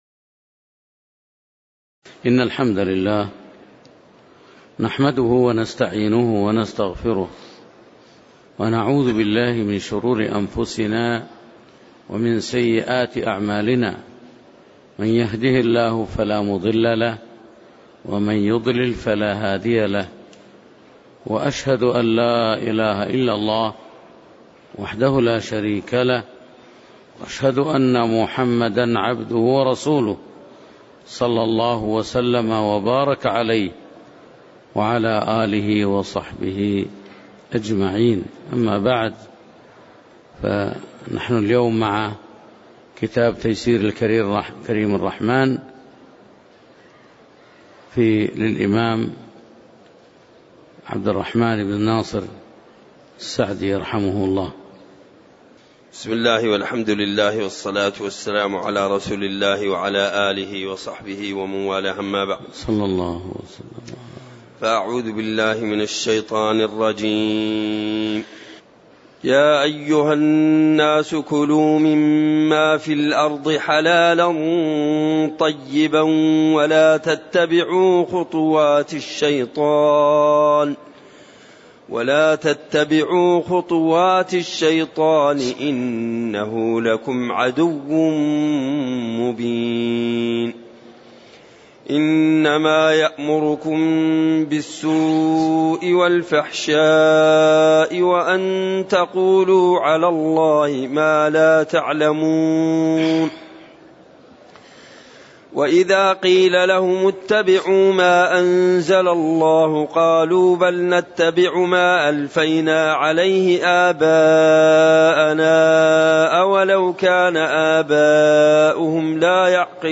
تاريخ النشر ٩ شعبان ١٤٣٨ هـ المكان: المسجد النبوي الشيخ